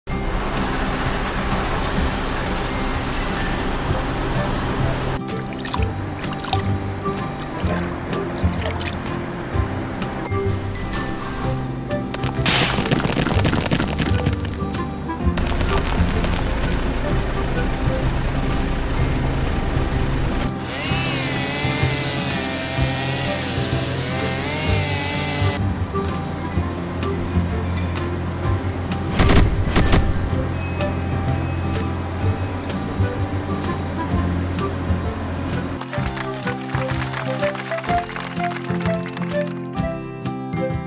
Furnace Work .